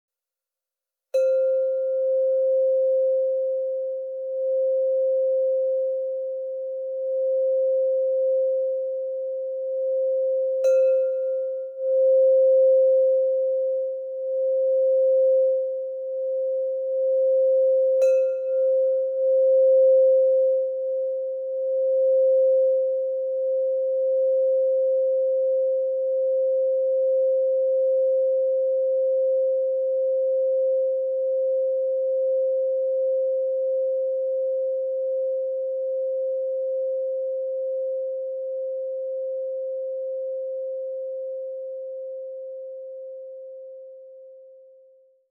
Meinl Sonic Energy Sound Bath Tube Chime Solfeggio Set, Champagnergold - 9 teilig (SBTSETSOL)
Bereichere deine Klangreisen mit dem Meinl Sonic Energy Sound Bath Tube Chime Solfeggio Set, das aus neun Tönen besteht.